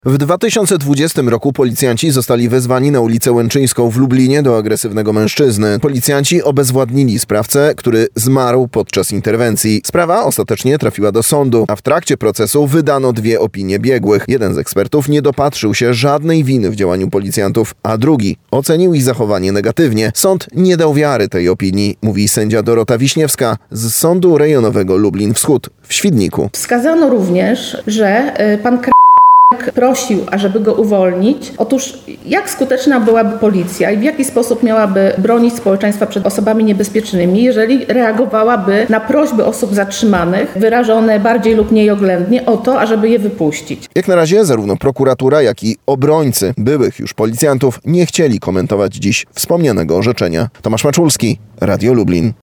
Na miejscu był reporter Radia Lublin.